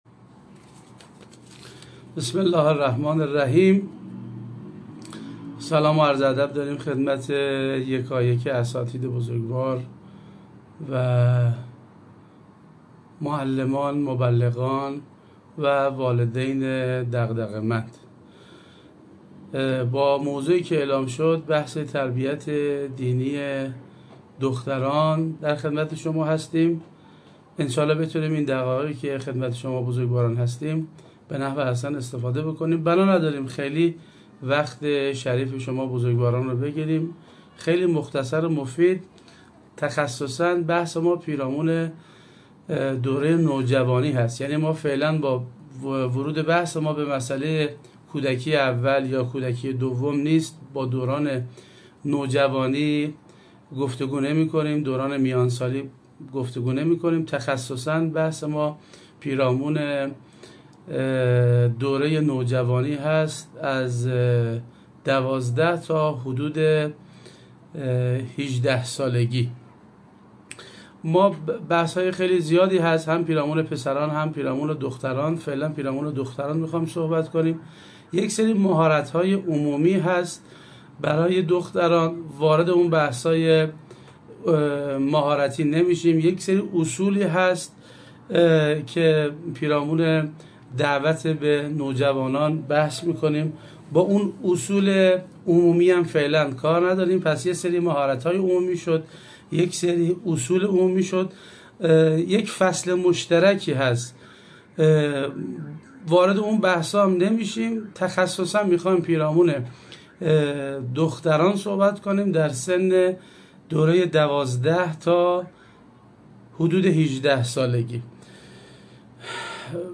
پخش زنده دوشنبه های نمازی-جلسه چهاردهم-با موضوع :شیوه های تربیت دینی دختران